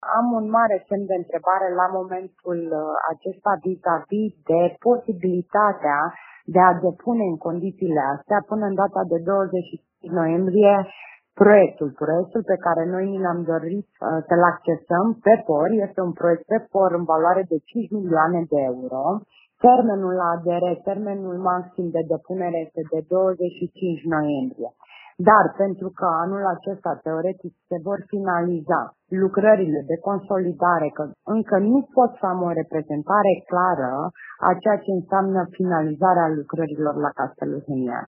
Cererea de finanțare pentru proiectul în valoare de 5 milioane de euro  riscă să nu poată fi depus în acest an, spune vicepreședintele Consiliului Județean Timiș, Roxana Iliescu.